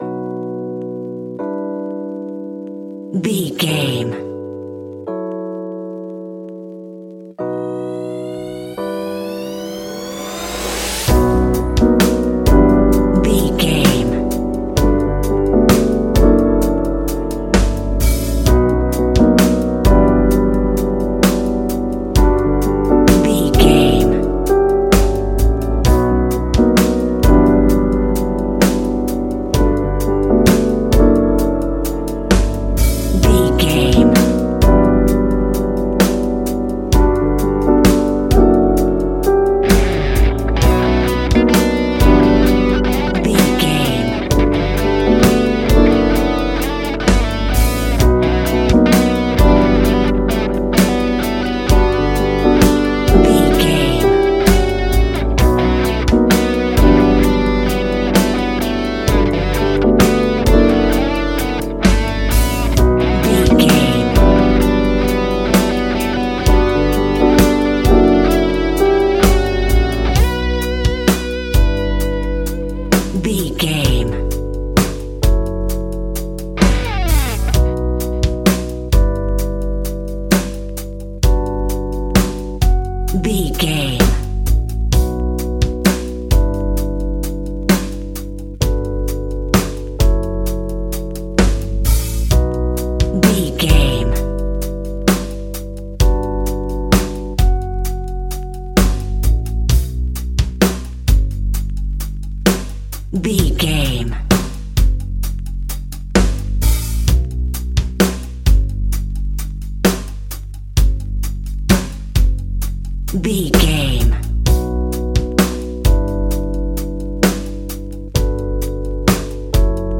Ionian/Major
laid back
Lounge
sparse
new age
chilled electronica
ambient
atmospheric
morphing